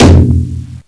taiko-normal-hitfinish.wav